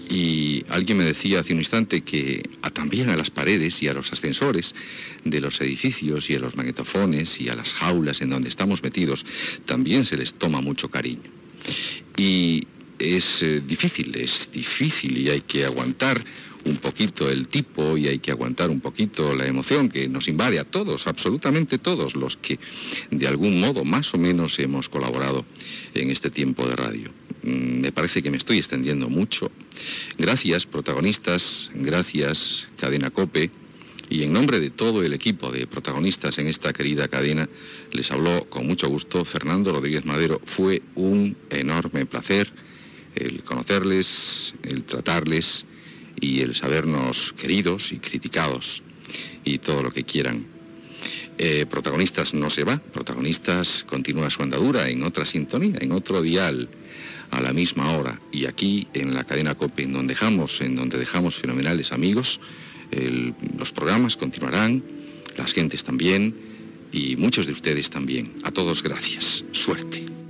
Comiat de Protagonistas a la COPE
Info-entreteniment